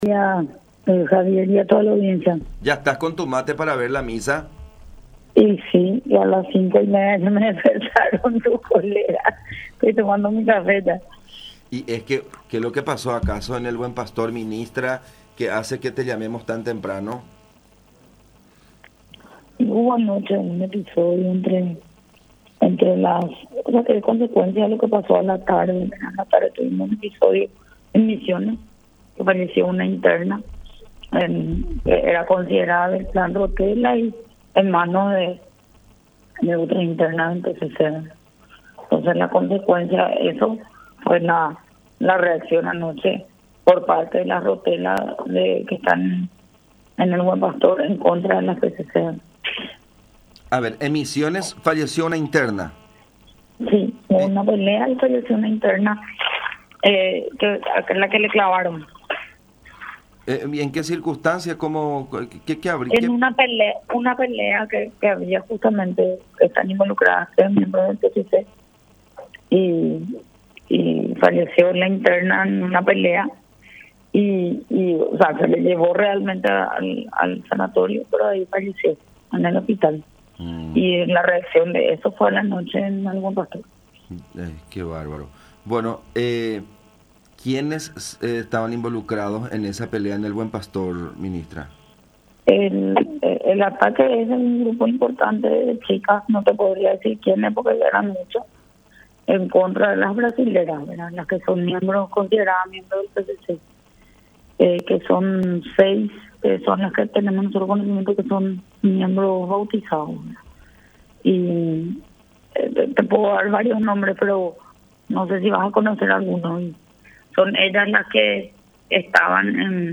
“Una interna del penal de Misiones falleció ayer, por un problema entre el Clan Rotela y el PCC. Fue durante una pelea que esta mujer falleció como consecuencia de una herida de arma blanca. Esta situación tuvo como consecuencia, un problema que se suscitó en el Penal del Buen Pastor. No tuvimos reporte de gente herida, pero sí algunos desmayos, por la tensión y la discusión entre las internas”, aseveró Cecilia Pérez, ministra de Justicia, en diálogo con La Unión.